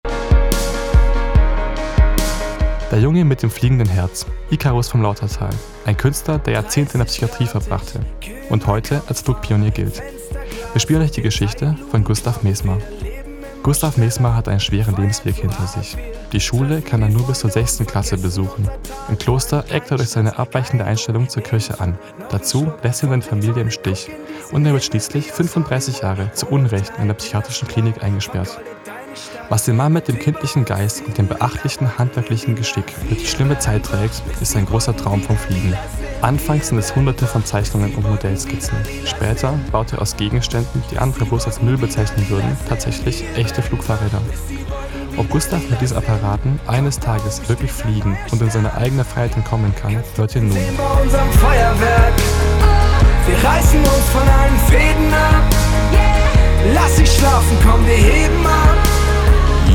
Hörspiel "Frei wie ein Vogel – Gustav Mesmer und sein Traum vom Fliegen"
Bei den Aufnahmen wurden sie von professionellen Sprecher*innen unterstützt.